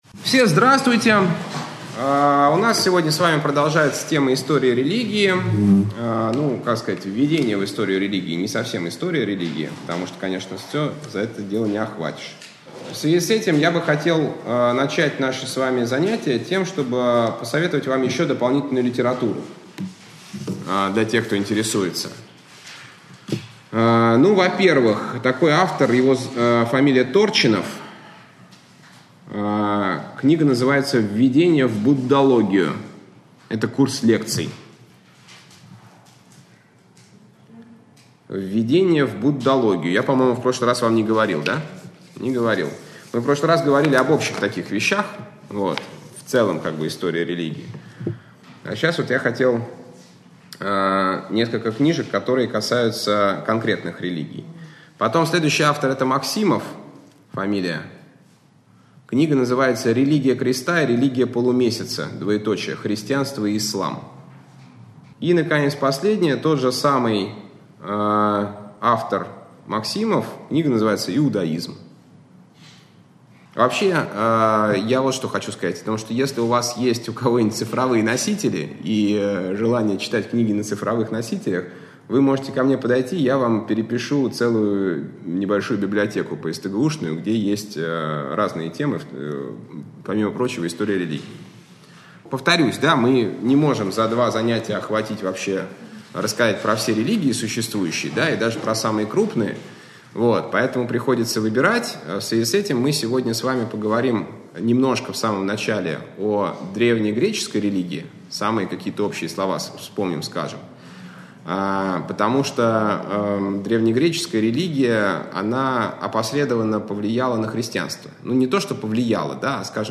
Общедоступный православный лекторий 2013-2014